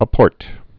(ə-pôrt)